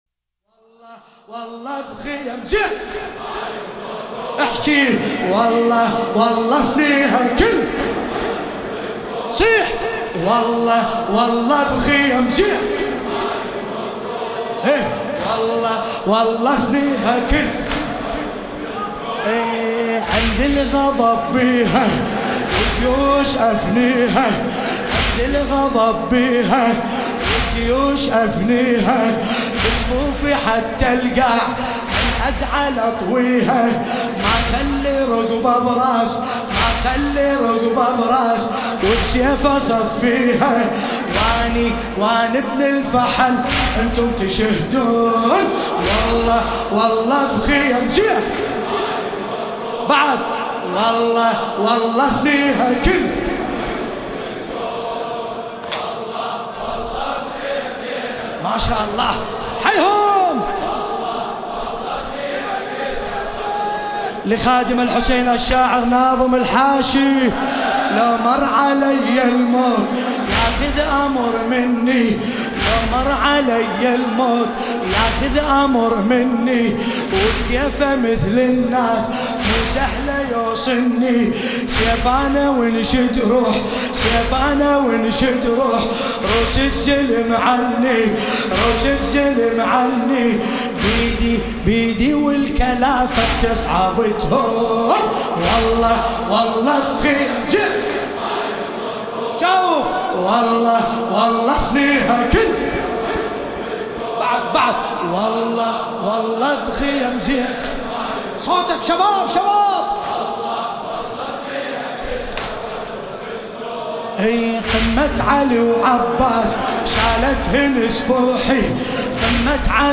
لطمیات ومراثی